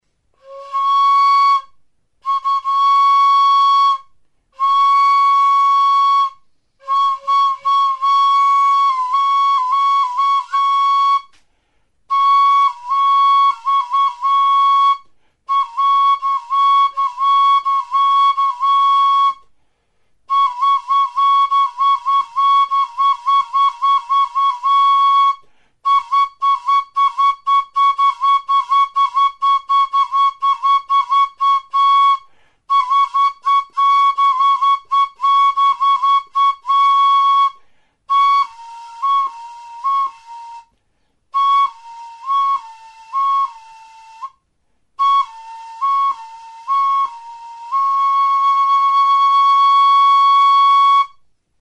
Aerófonos -> Flautas -> Recta (de una mano) + flautillas
Grabado con este instrumento.
Ez du tonu aldaketarako zulorik, baina beheko muturra irekia dago, hodiaren beheko zuloa itxiz eta irekiz eta putz egiterakoan indarra aldatuz, harmonikoak erabiliz doinu aldaketak lortzen dira.